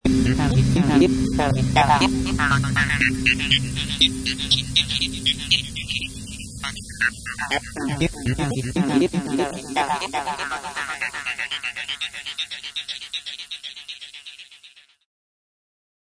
Wild FX at the end of an original song